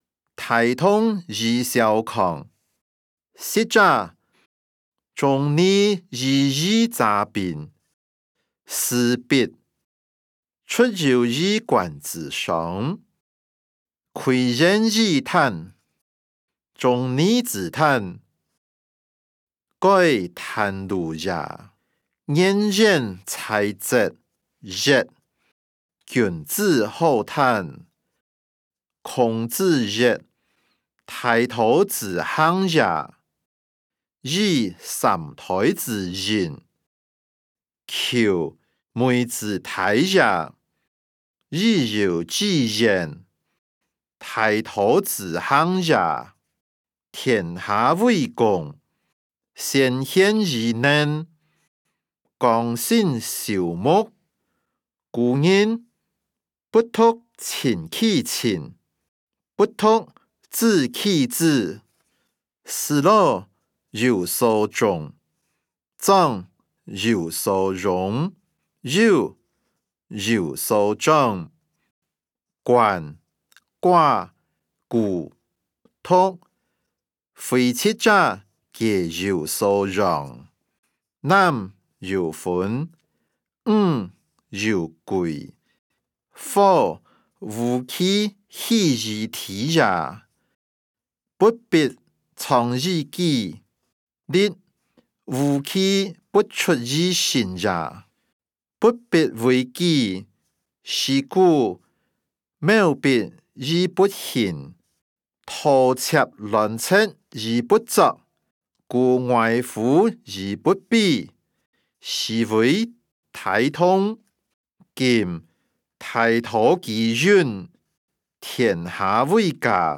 經學、論孟-大同與小康音檔(饒平腔)